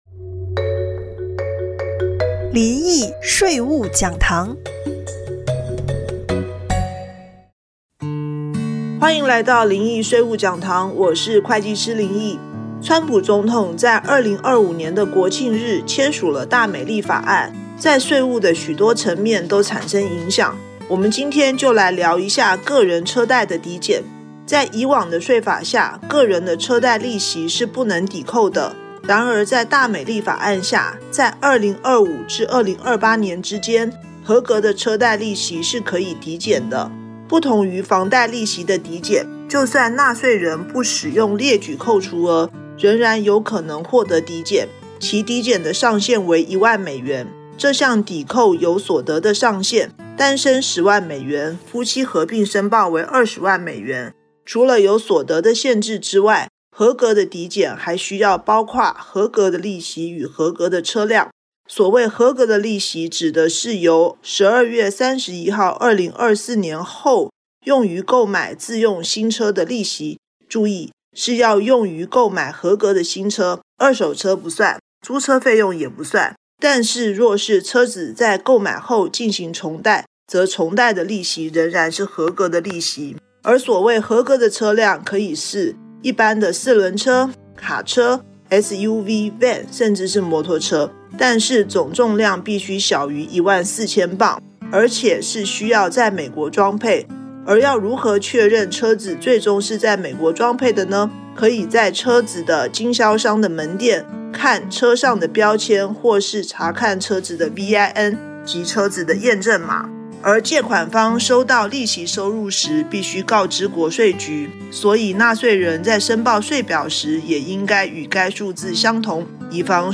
電台訪談